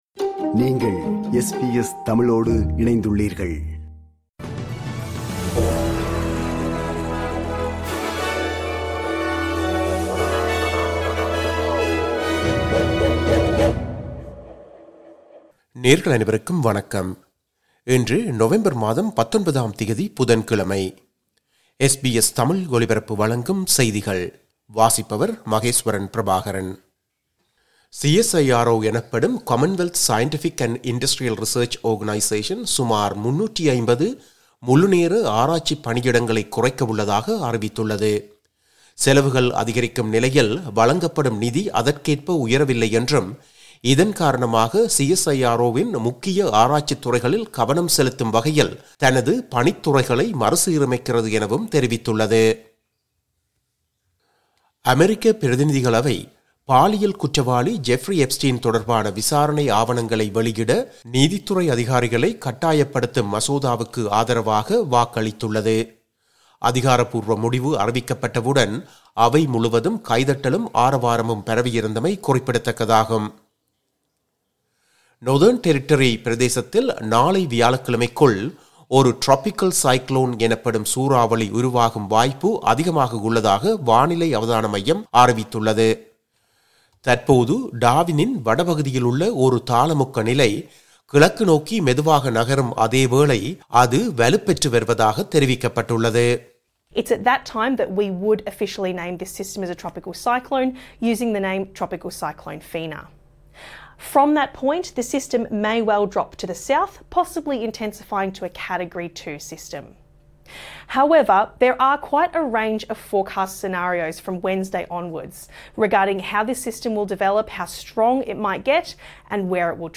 இன்றைய செய்திகள்: 19 நவம்பர் 2025 புதன்கிழமை
SBS தமிழ் ஒலிபரப்பின் இன்றைய (புதன்கிழமை 19/11/2025) செய்திகள்.